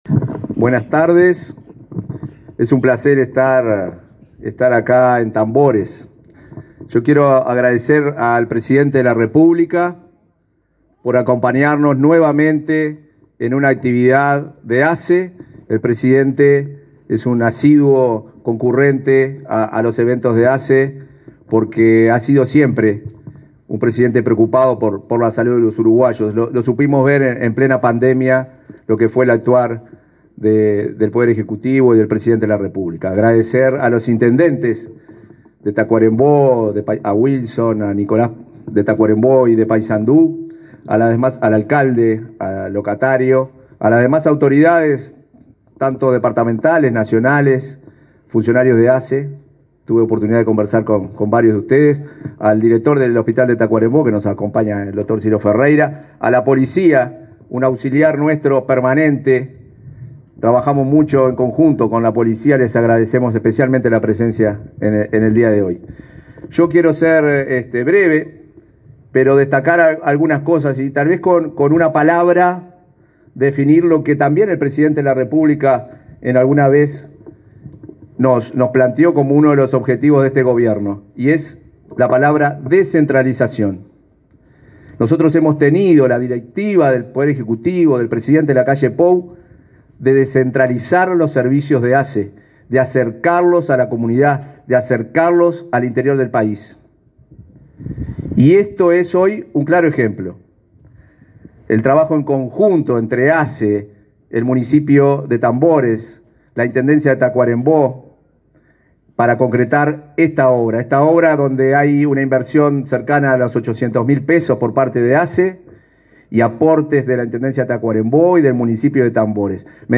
Palabras del presidente de ASSE, Marcelo Sosa 27/06/2024 Compartir Facebook X Copiar enlace WhatsApp LinkedIn Este 27 de junio, el presidente de la República, Luis Lacalle, inauguró a ampliación de la policlínica de la Administración de los Servicios de Salud del Estado (ASSE), ubicada en la localidad de Tambores, departamento de Tacuarembó. En el eventó disertó el presidente de ASSE, Marcelo Sosa.